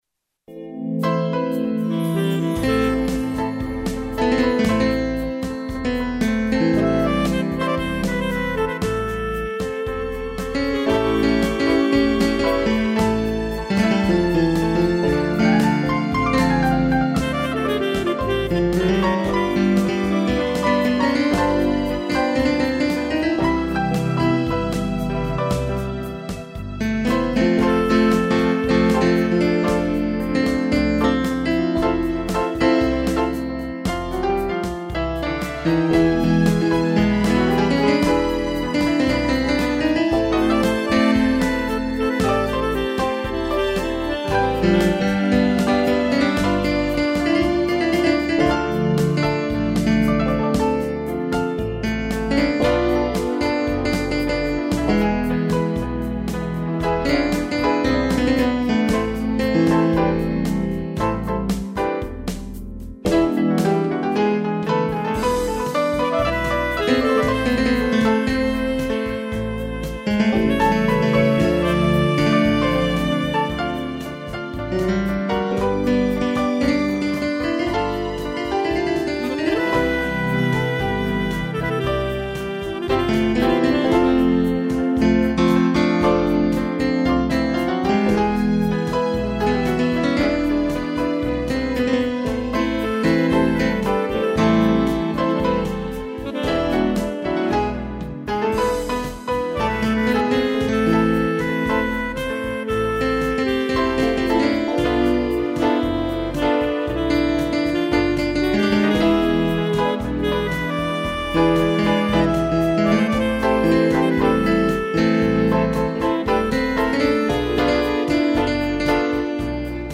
piano, sax e strings
instrumental